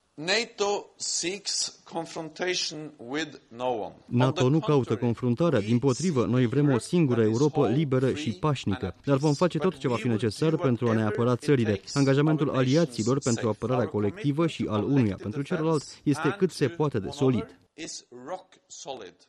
Prezent la ceremonia de inaugurare a comandamentului de la Vilnius, secretarul general al NATO, Jens Stoltenberg a ţinut să sublinieze astăzi.